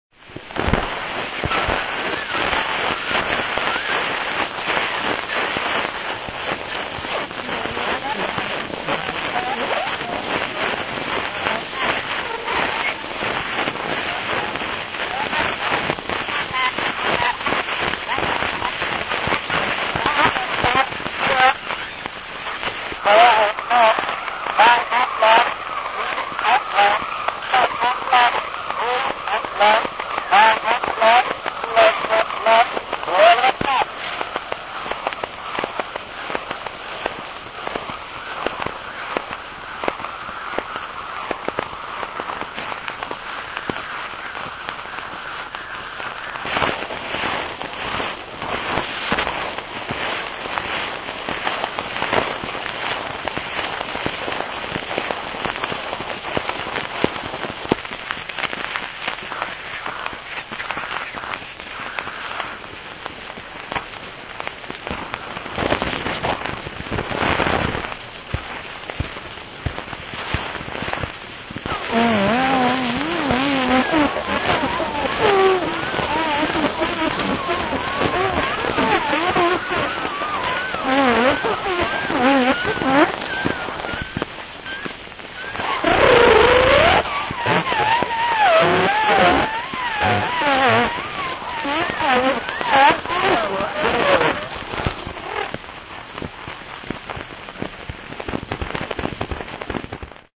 The world earliest playable phonograph recording – voices from 1878 of an experimental talking clock.
• 0:00-0:21 Indistinct speech
• 0:34-1:10 'Silent' period
• 1:11-1:40 Indistinct speech, possibly reversed
Portions of the fourth section (1:11-1:40) sound as if they may have been recorded in reverse, that is, with the phonograph cranked counter-clockwise.